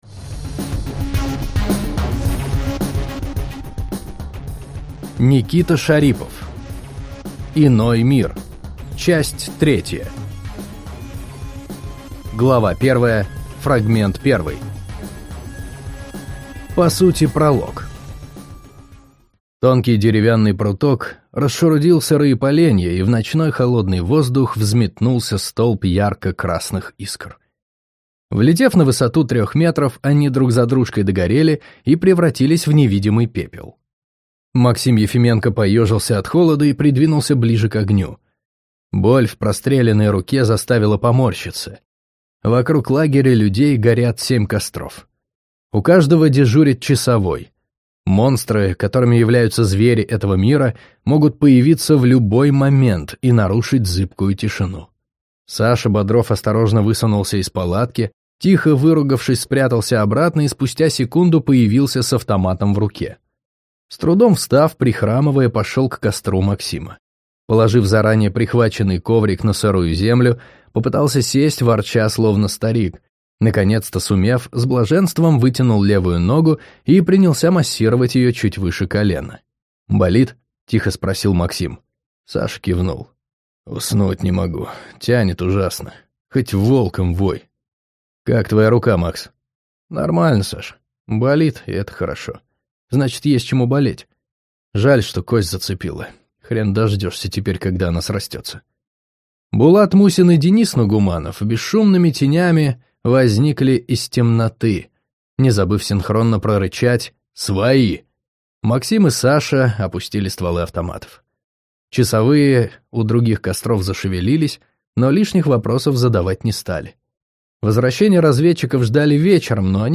Аудиокнига Иной мир. Часть третья | Библиотека аудиокниг